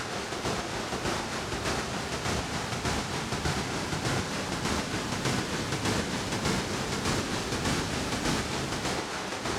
STK_MovingNoiseB-100_03.wav